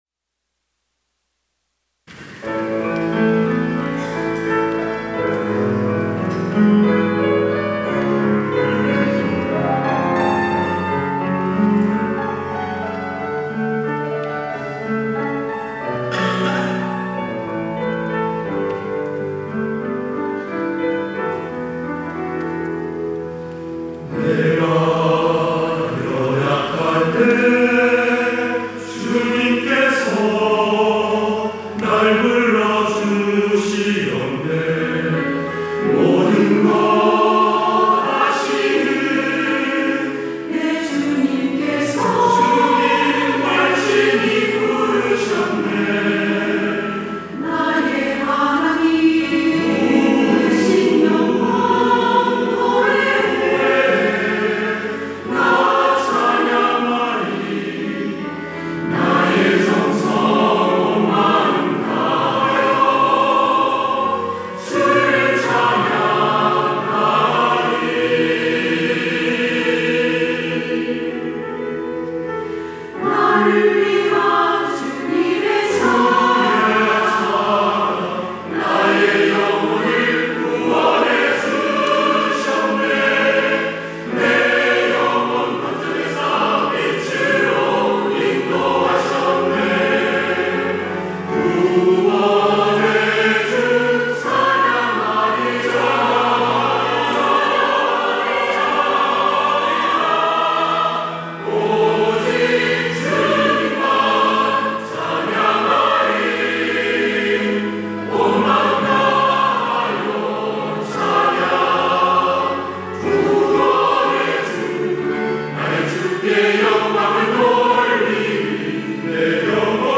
할렐루야(주일2부) - 구원의 주님을 찬양하리
찬양대